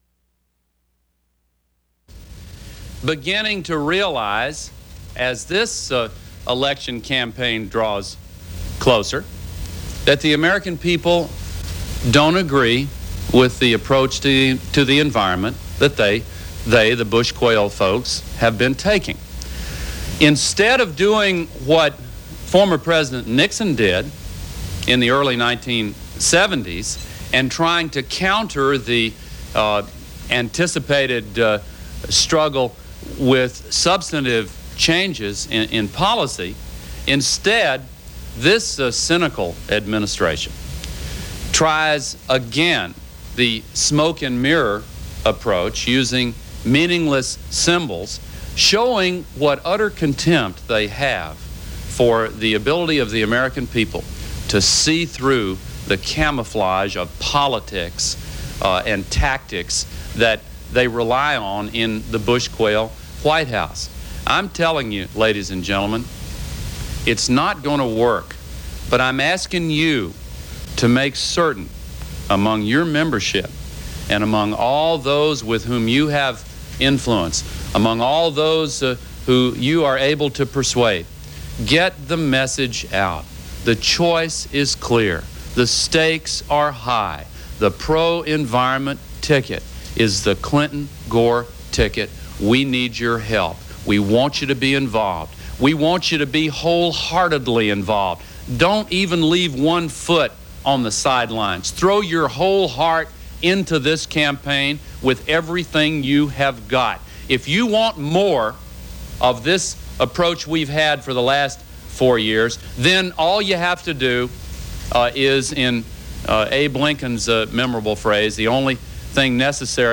Al Gore, U.S. vice presidential candidate, addresses the Sierra Club on environmental issues
Broadcast on CNN, July 15, 1992.